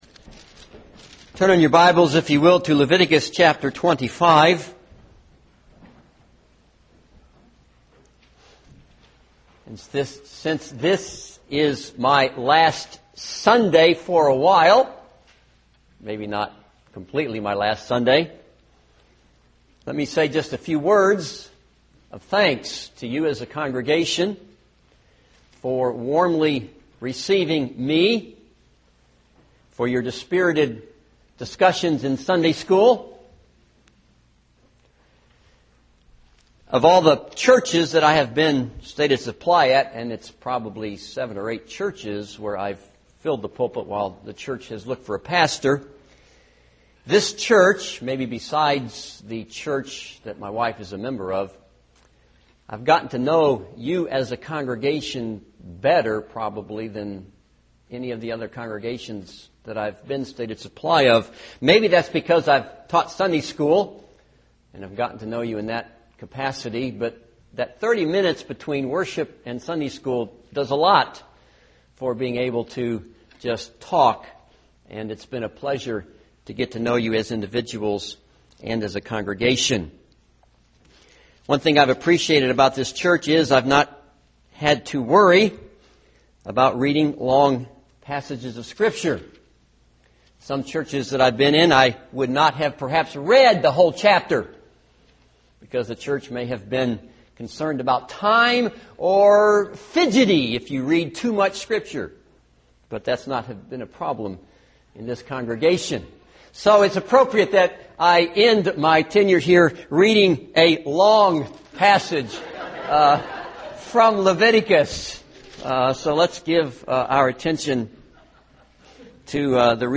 This is a sermon on Leviticus 25.